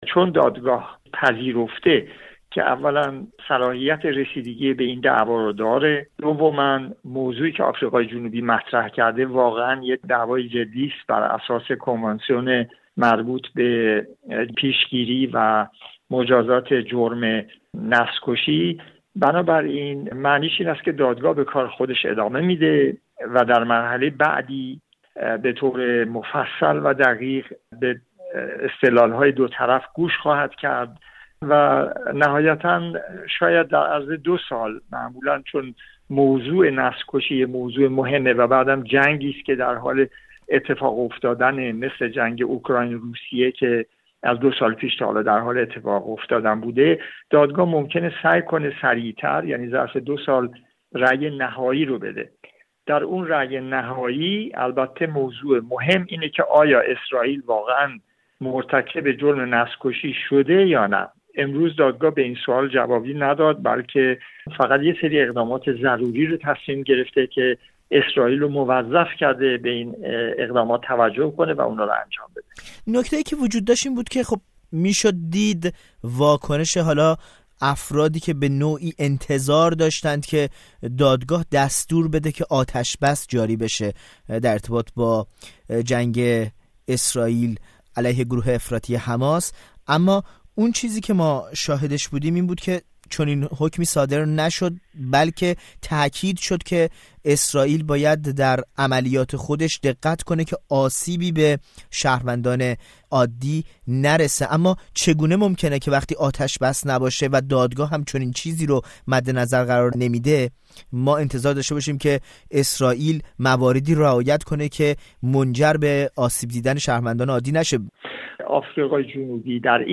گفت‌‌وگو